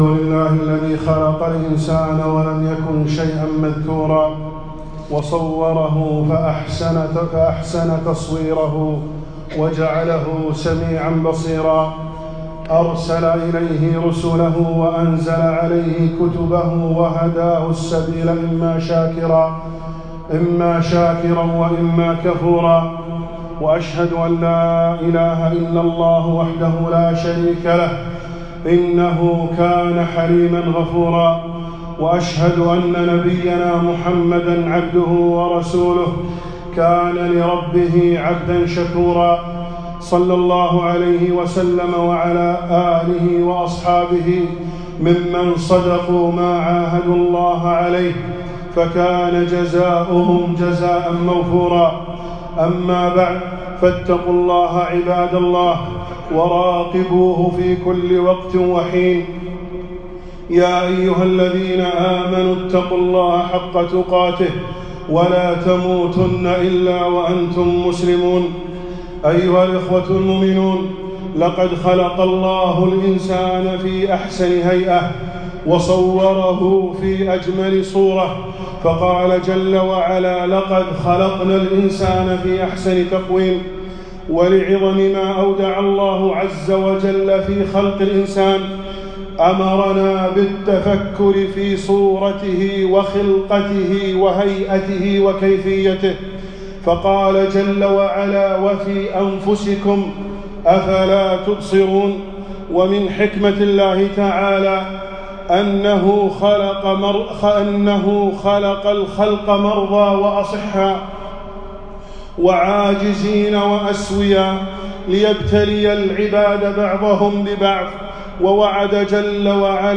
خطبة - رعاية الإسلام لذوي الإحتياجات الخاصة